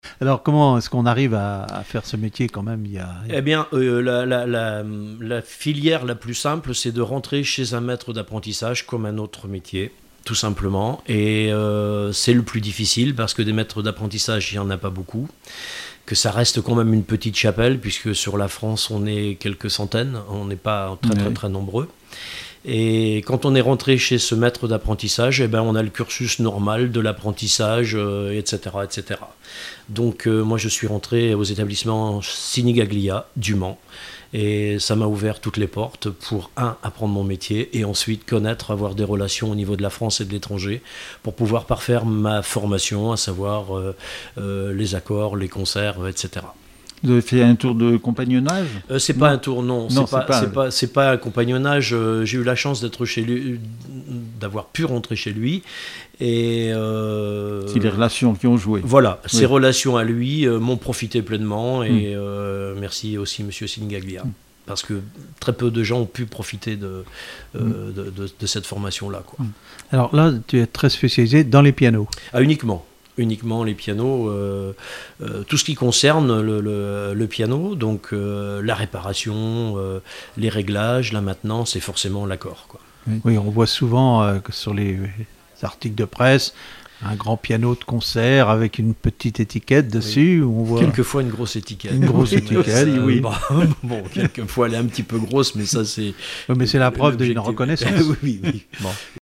Emissions de la radio RCF Vendée
Témoignage sur le métier de facteur accordeur de piano